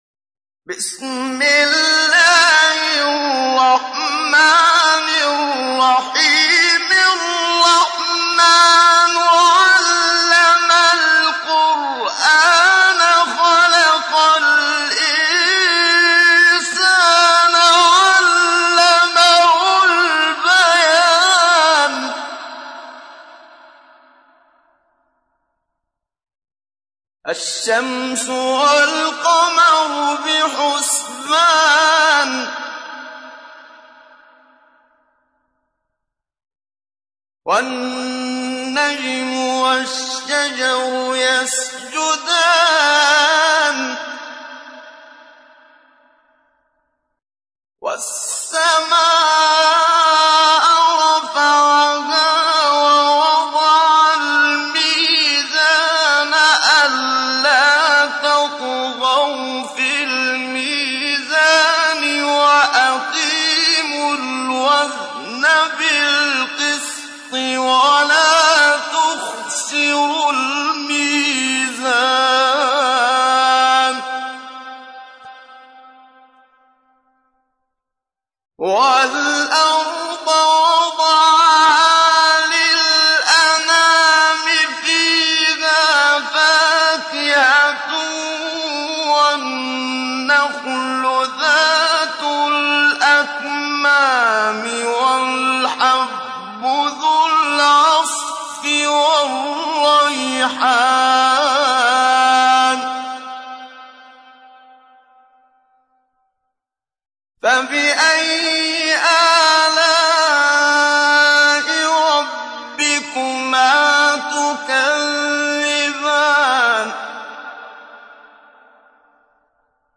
تحميل : 55. سورة الرحمن / القارئ محمد صديق المنشاوي / القرآن الكريم / موقع يا حسين